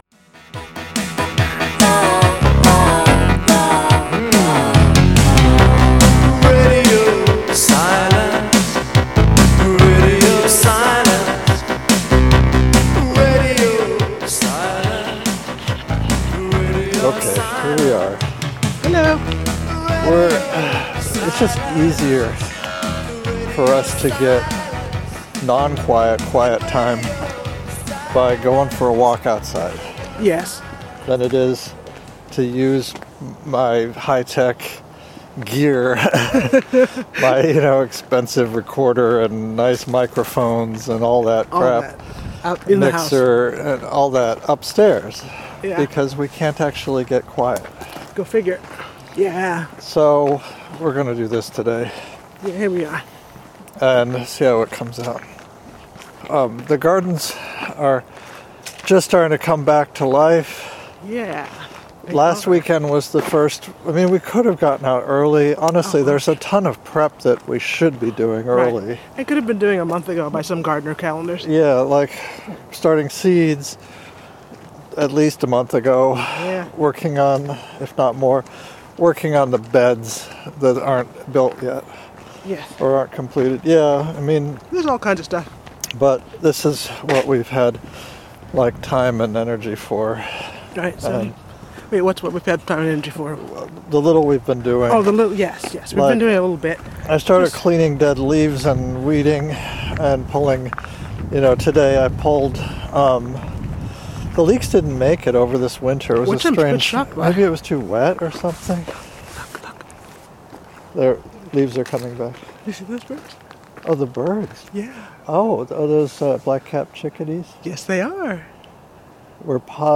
It is audio-only.